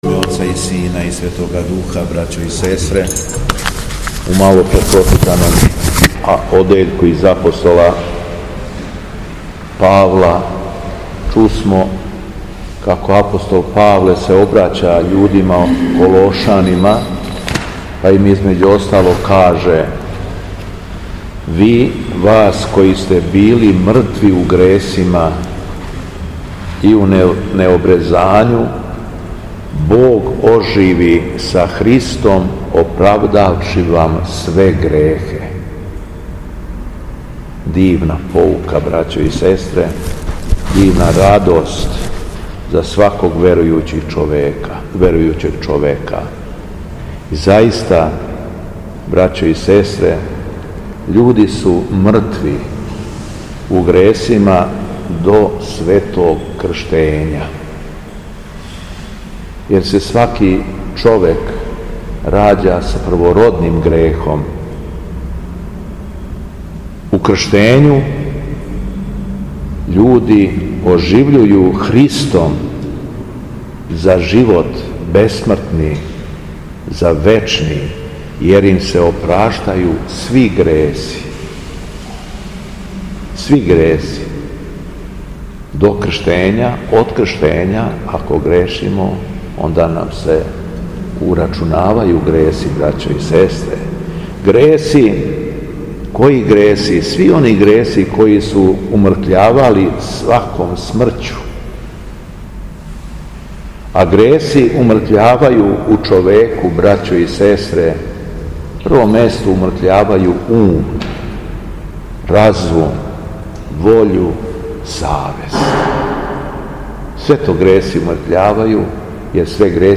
Беседа Његовог Високопреосвештенства Митрополита шумадијског г. Јована